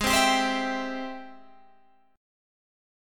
Ab+M7 Chord
Listen to Ab+M7 strummed